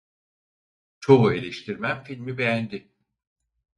Pronounced as (IPA) /e.leʃ.tiɾˈmen/